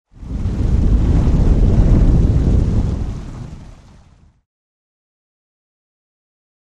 DeepRumbleWaterMov PE313101
Deep Rumble With Water Movement